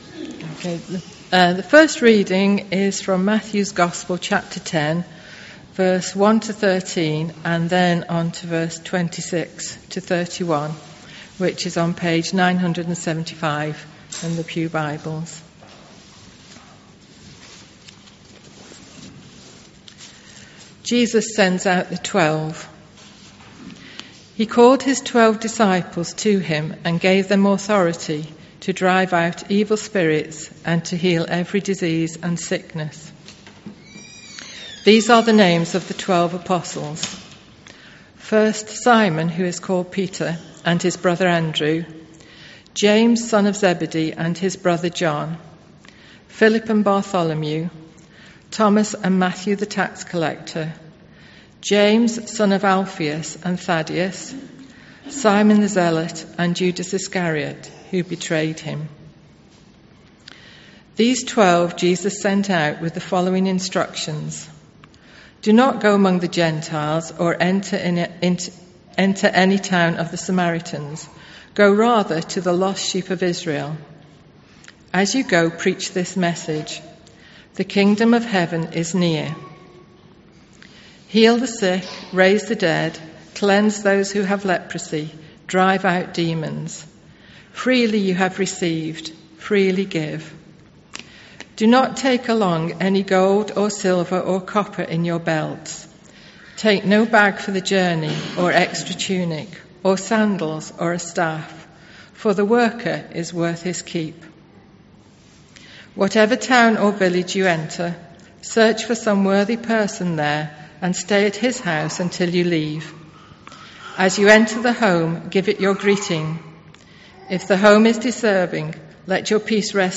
An audio file of the service is now available to listen to.